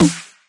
描述：沉重的小号是所有类型低音音乐的完美选择！
标签： 圈套 回响贝斯 房子 鼓和低音
声道立体声